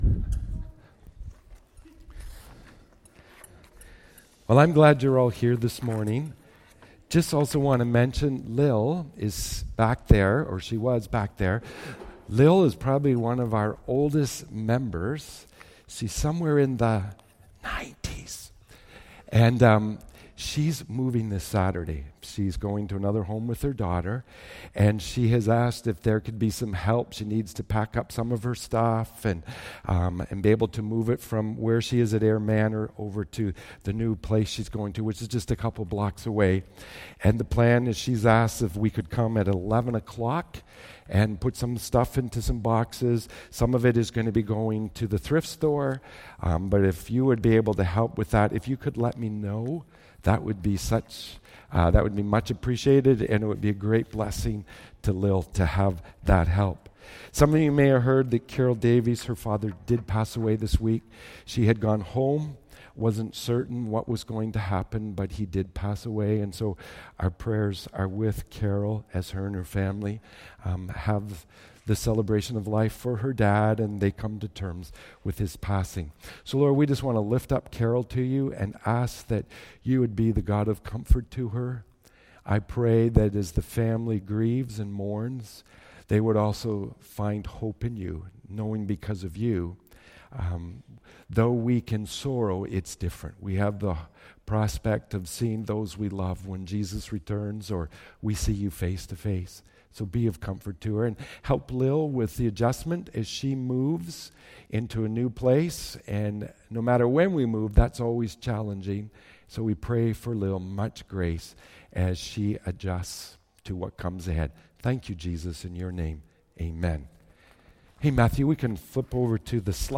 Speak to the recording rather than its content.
Easter Sunday 2016